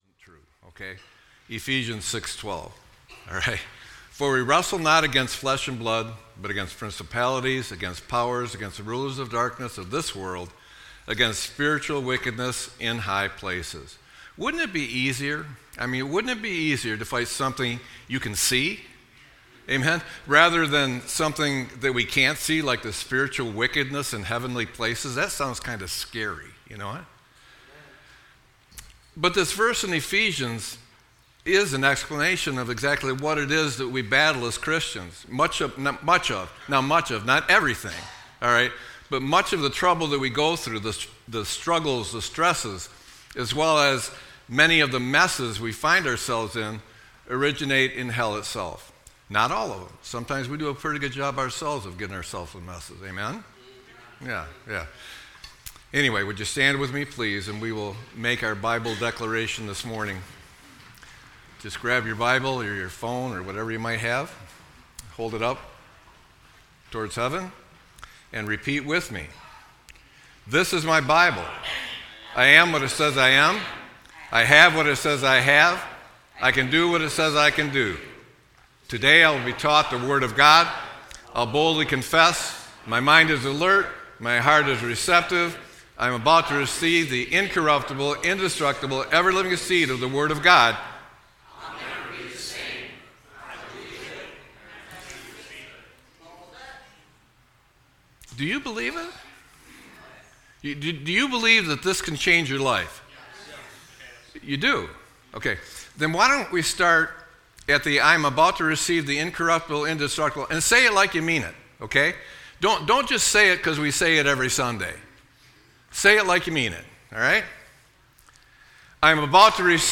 Sermon-9-21-25.mp3